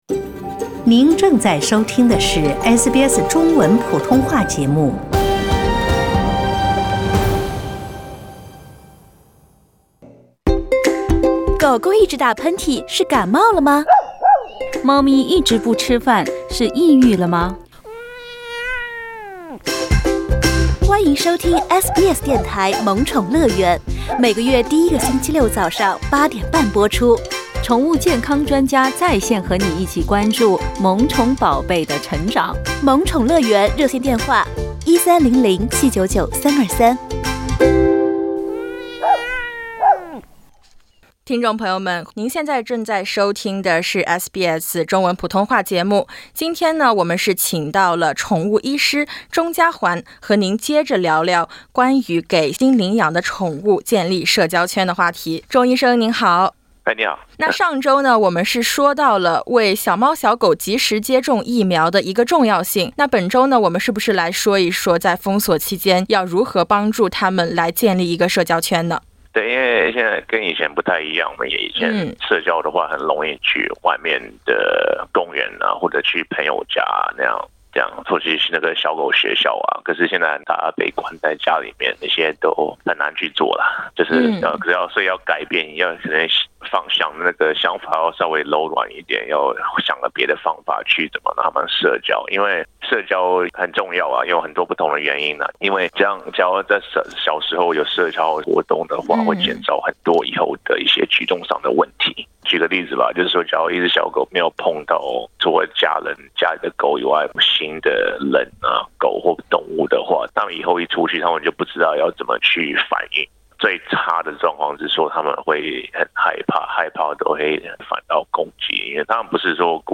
欢迎点击图片音频，收听完整采访。